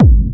VEC3 Bassdrums Dirty 03.wav